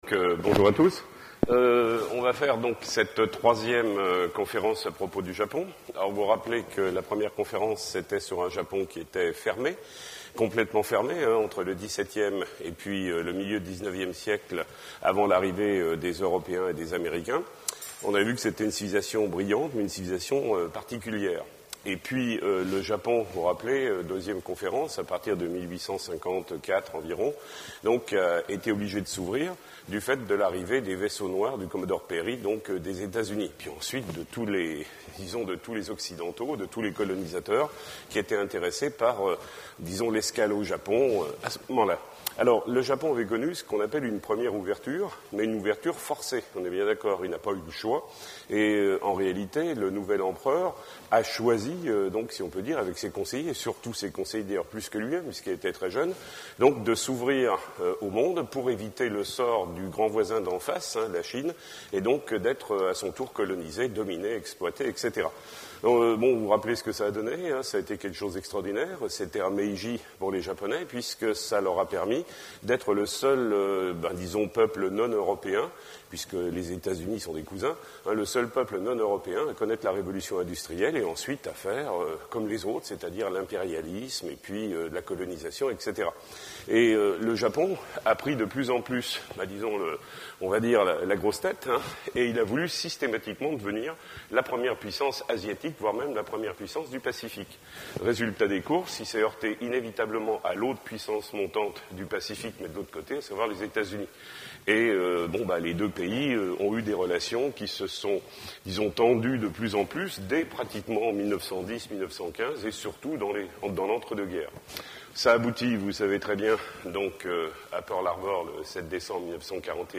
Histoire du Japon - Le Japon après 1945 Conférences | Université pour Tous de Bourgogne
Lieu de la conférence Maison des syndicats 2 rue du Parc 71100 Chalon sur Saône